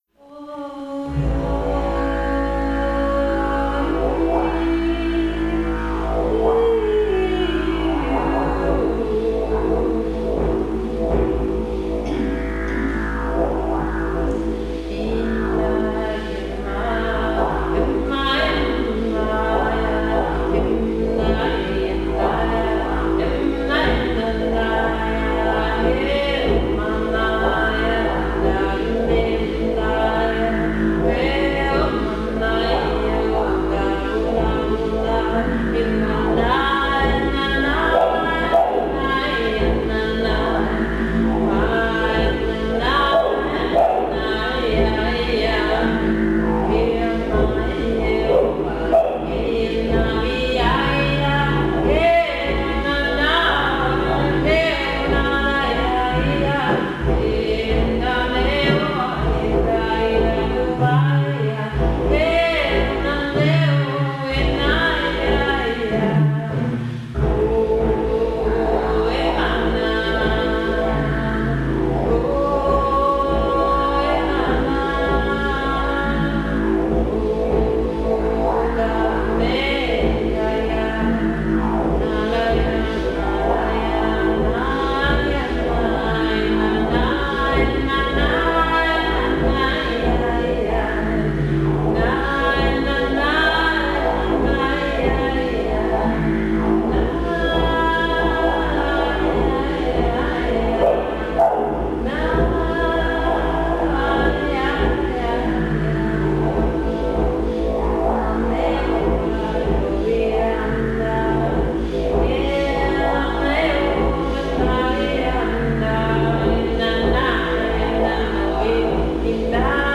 I use agave flowers to make didgeridoos.
pitas en diferentes procesos tocando el didge de pita en la